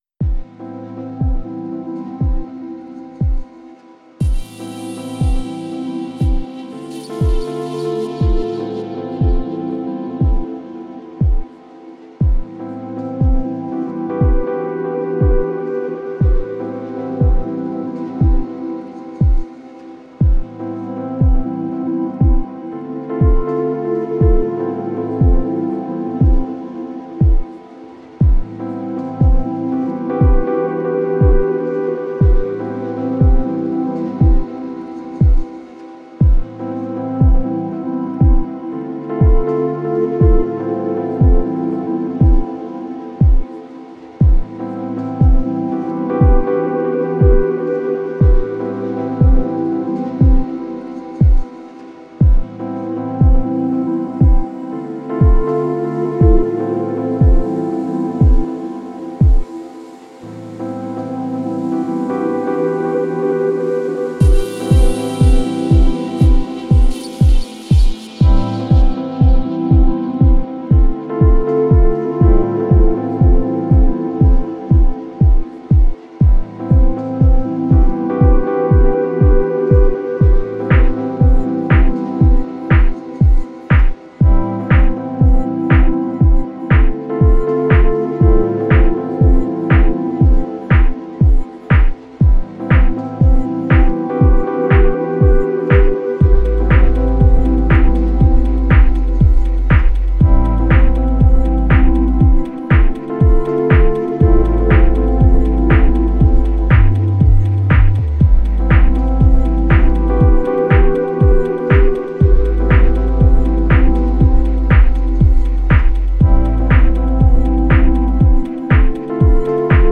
Genre: Dub Techno/Ambient.